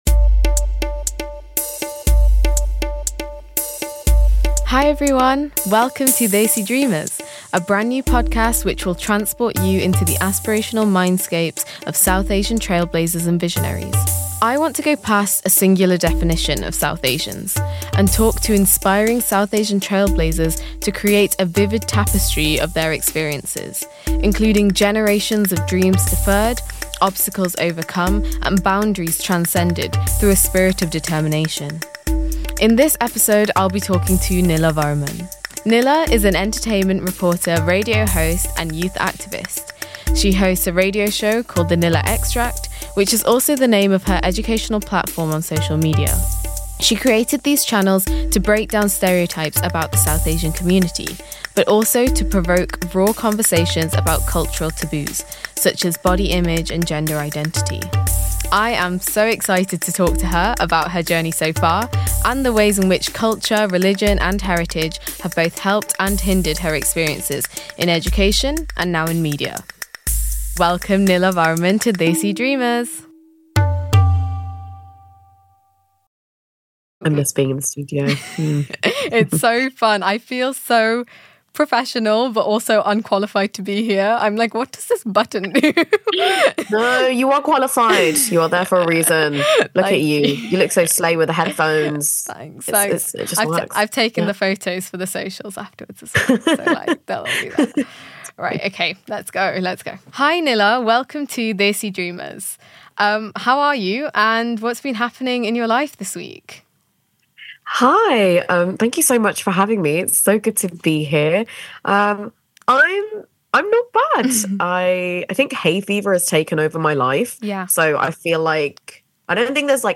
Tune in for an honest, inspiring conversation about identity, representation, and the power of using your voice.